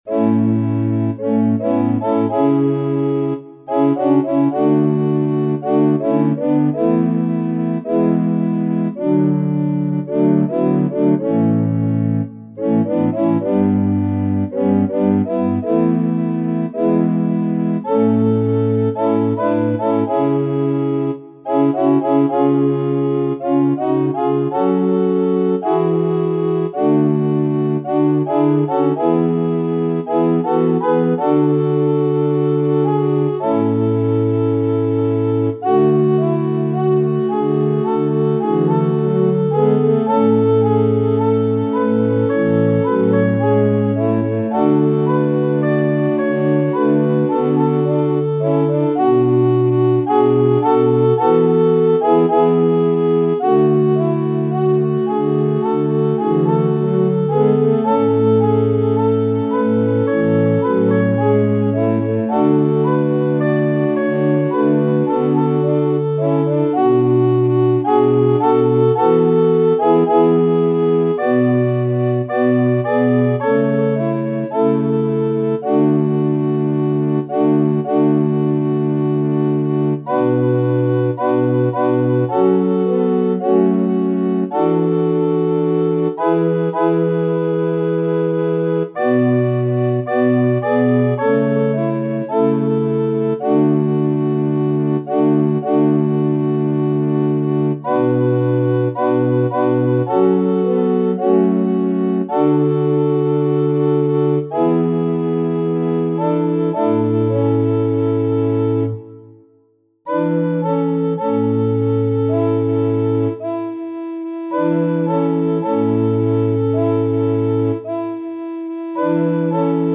WERSJA ŚPIEWANA
CHÓR